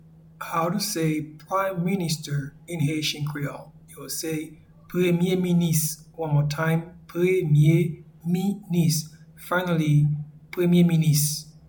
Pronunciation and Transcript:
Prime-minister-in-Haitian-Creole-Premye-minis.mp3